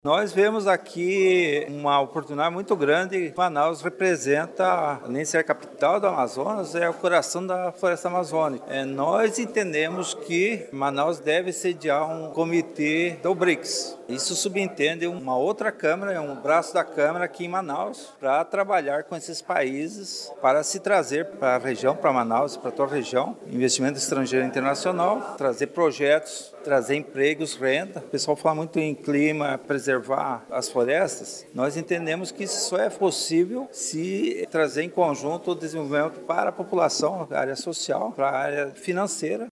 A reunião ocorreu no Mirante Lúcia Almeida, localizado no Centro Histórico de Manaus.
SONORA-1-REUNIAO-ECONOMIA-MANAUS-.mp3